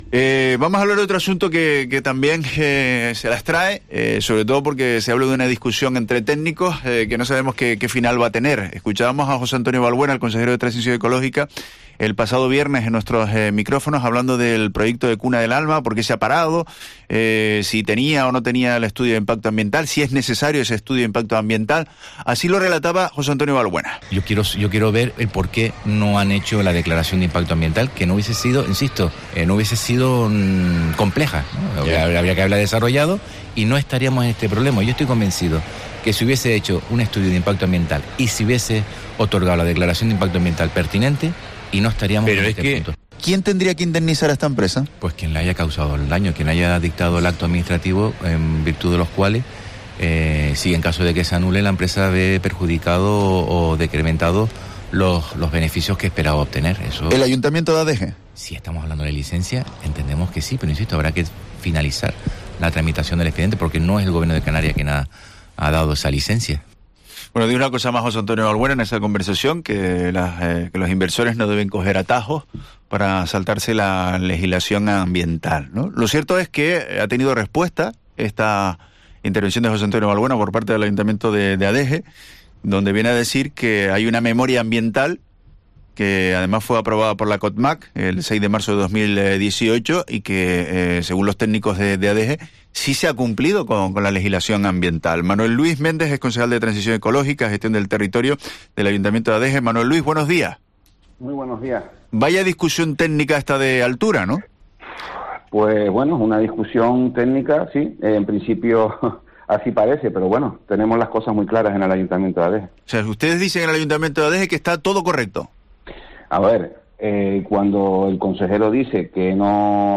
Manuel Luis Méndez, concejal de Gestión del Territorio de Adeje, sobre la paralización de Cuna del Alma